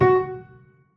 FSharp.wav